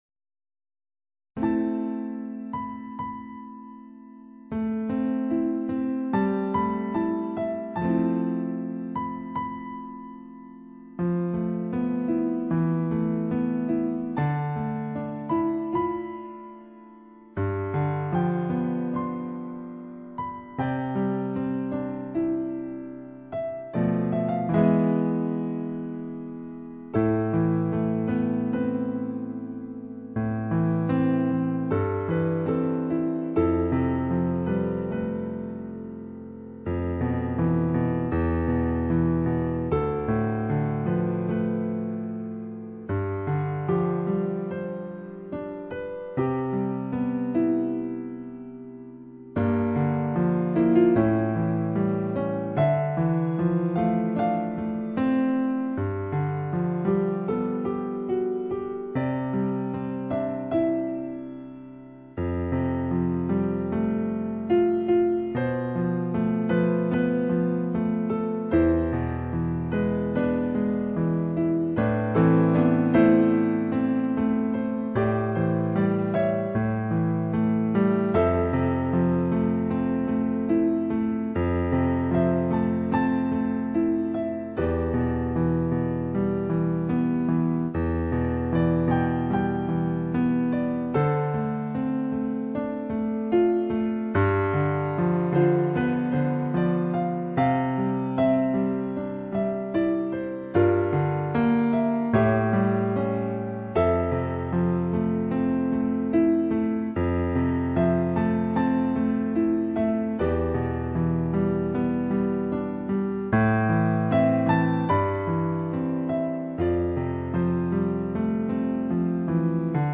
钢琴版本的这首也是一首经典作品，旋律舒缓，静静流淌，慢慢回味逝去的美好回忆。